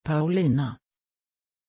Pronunciation of Paulina
pronunciation-paulina-sv.mp3